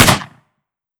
12ga Pump Shotgun - Gunshot B 004.wav